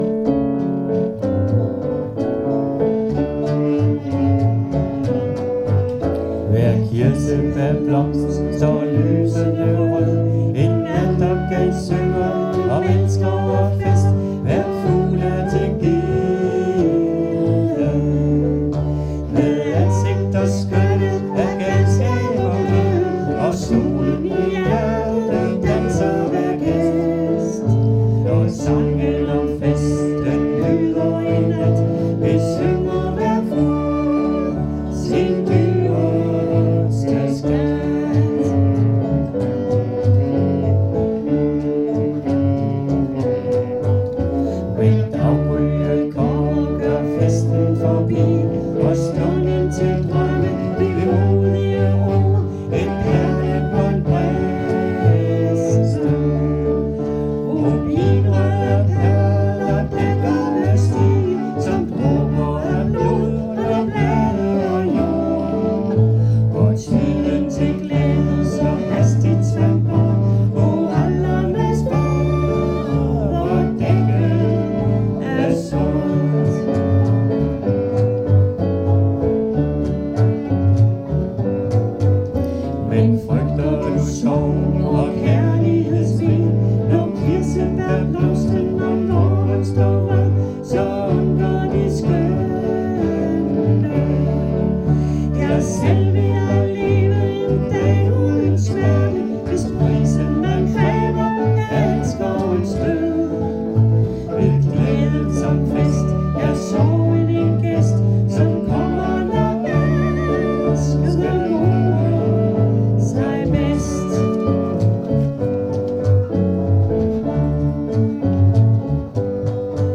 Øveaften 23. oktober 2024: